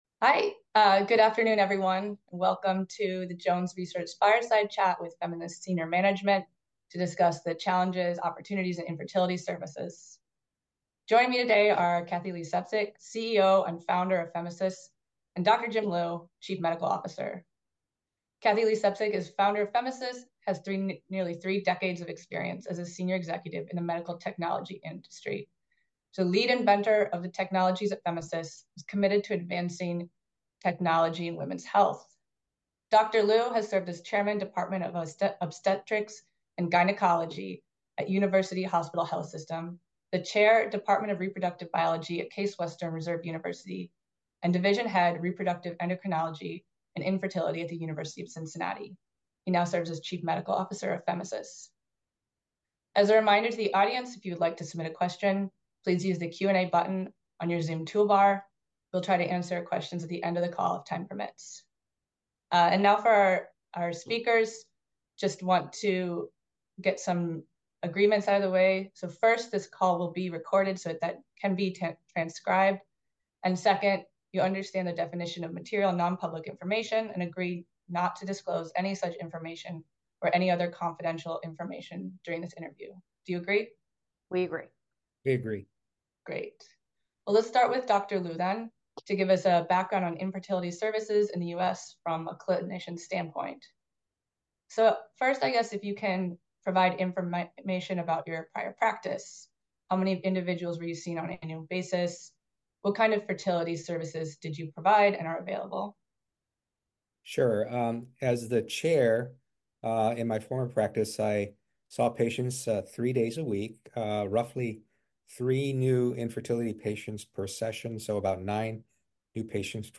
Fireside Chat